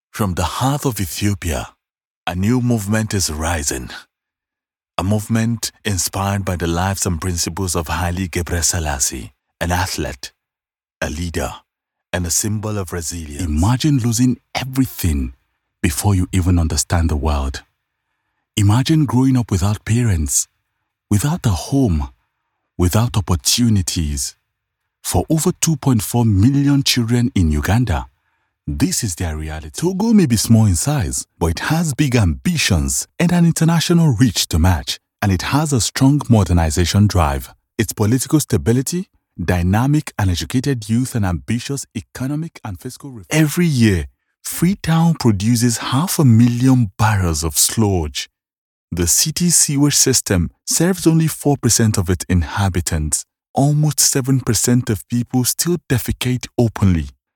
a deep, warm baritone voice with authentic African and Nigerian accents
Documentaries
I specialize in recording voiceovers with authentic African accents, including West African and Sub saharan African accent.
Fully soundproofed booth
BaritoneBassDeep